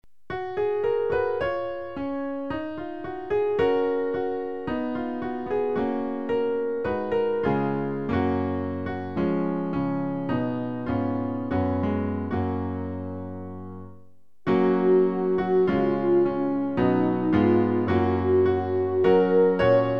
Klavier-Playback zur Begleitung der Gemeinde
(ohne Gesang)